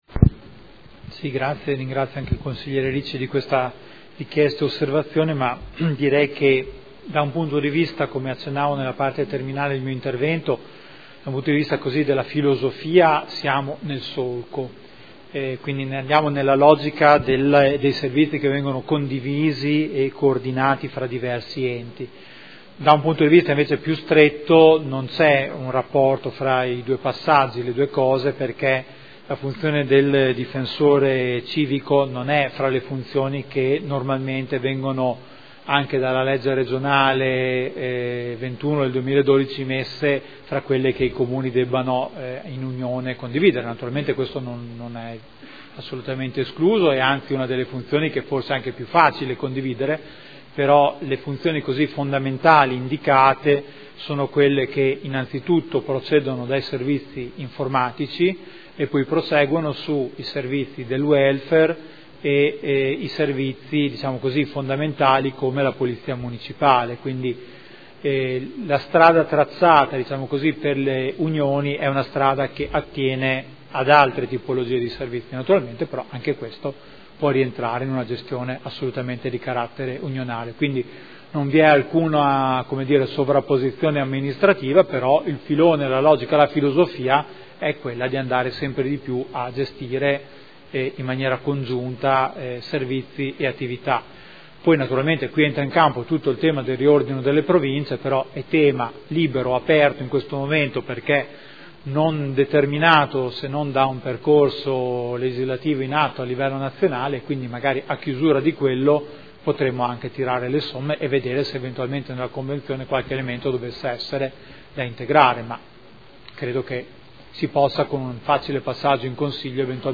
Giuseppe Boschini — Sito Audio Consiglio Comunale
Seduta del 16 gennaio. Proposta di deliberazione: Convenzione per l’utilizzo del Difensore Civico Territoriale – Approvazione.